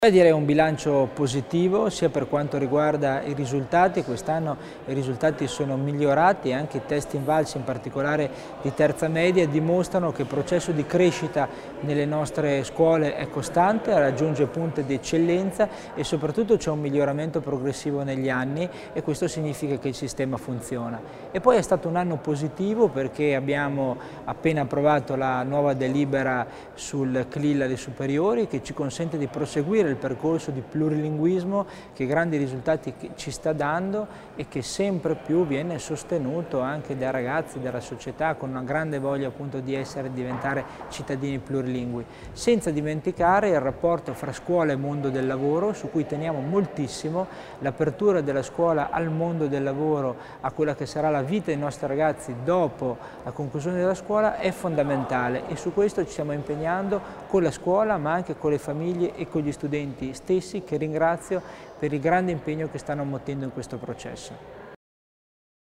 L'Assessore Mussner spiega lo stato di salute della scuola ladina